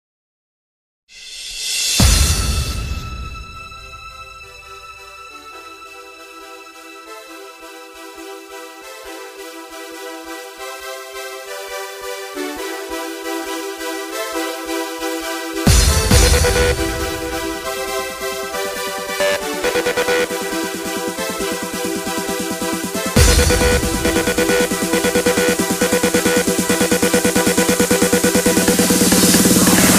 sandstorm_14198.mp3